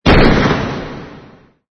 DISPARO DISPARO SECO
Ambient sound effects
disparo_DISPARO_SECO.mp3